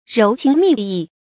柔情蜜意 róu qíng mì yì 成语解释 温柔甜蜜的情意。